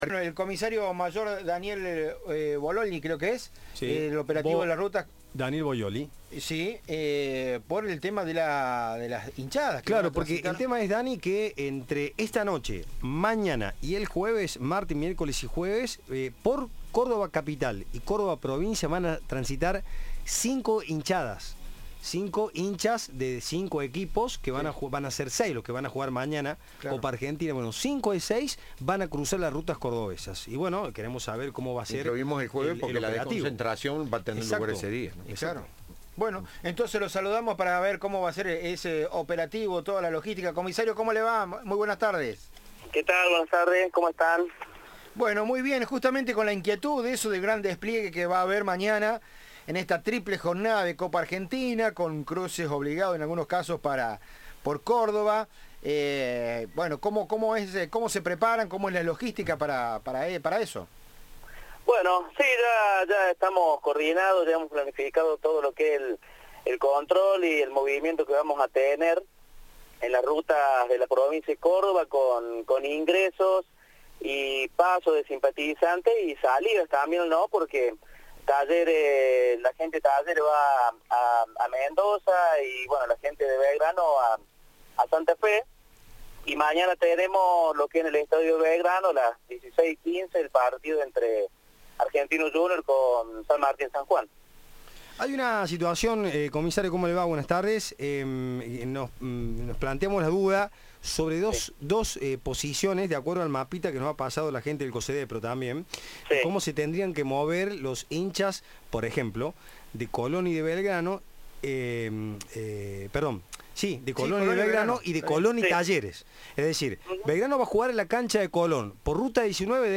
Cadena 3 habló con Federico Chiapetta, subsecretario de Deportes de Mendoza.